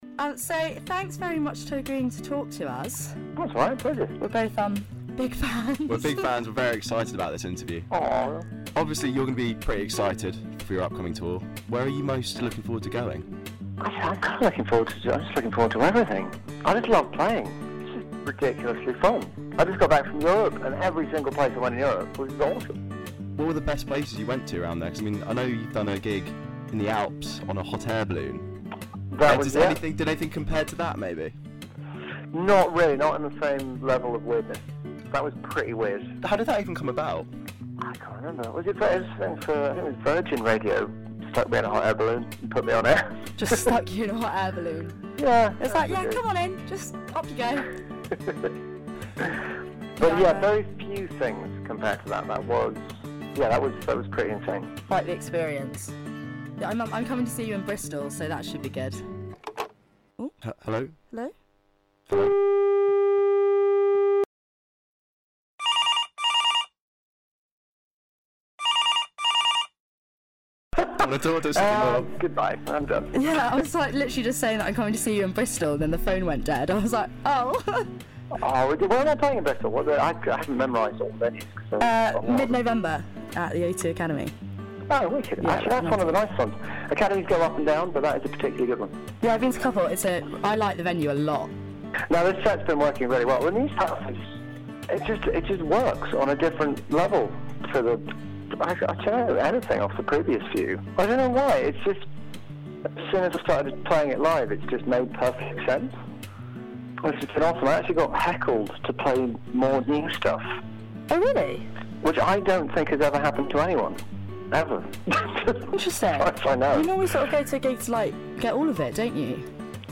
An Interview with Newton Faulkner